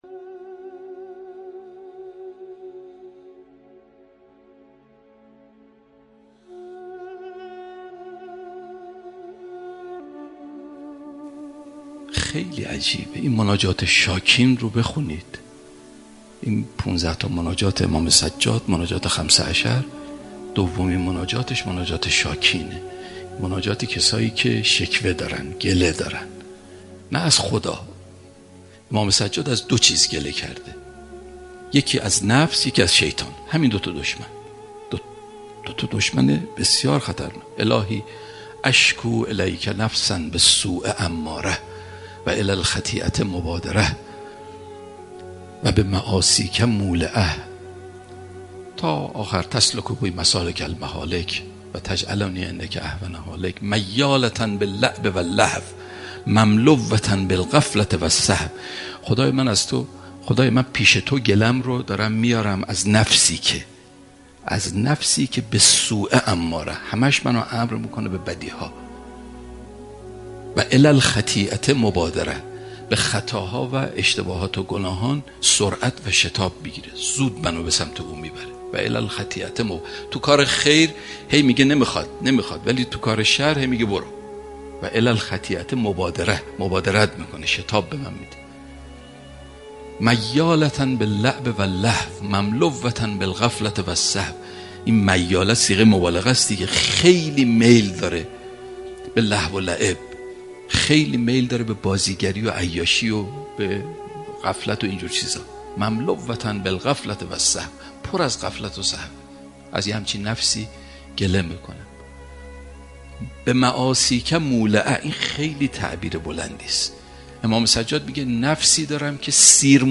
دومین جلسه از بیانات کوتاه و معرفتی
سخنرانی مباحث معرفتی